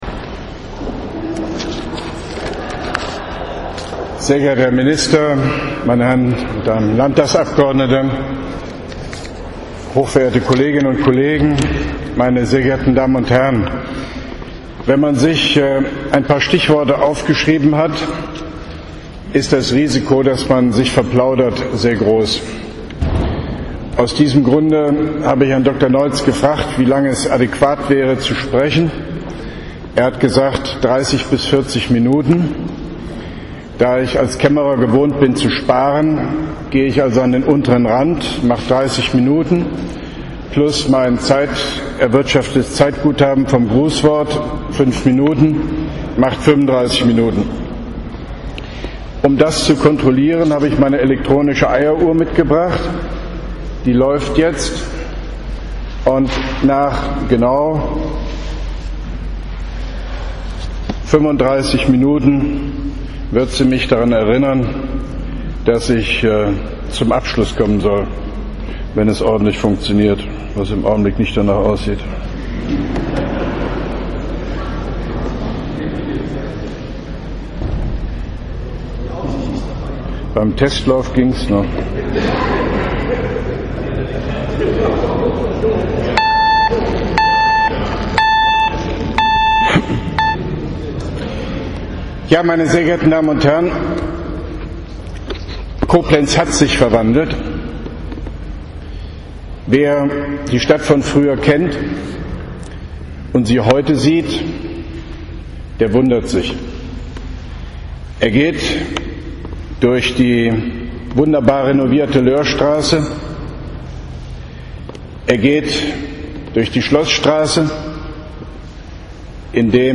JoHo-Rede-Städtetag-23.9.11.mp3